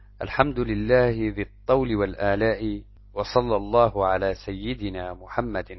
ch_00_arabic_tts_dataset_9.mp3